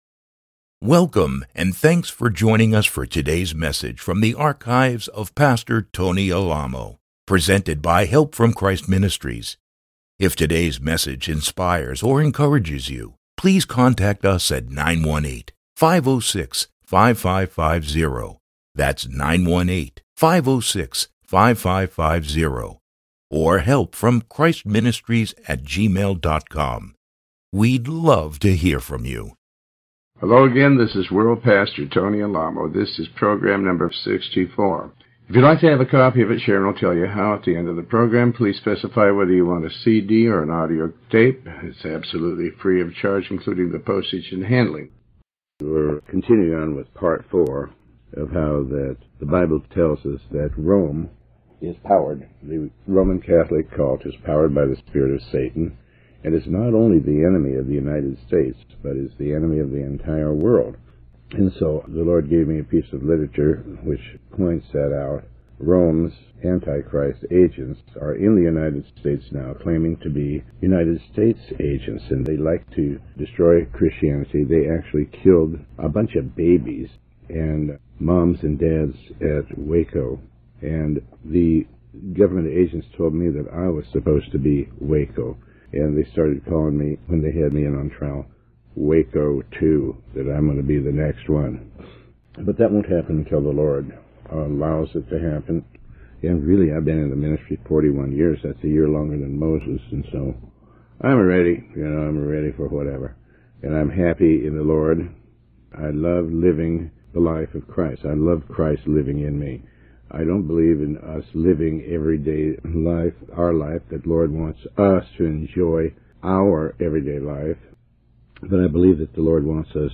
Sermon 64A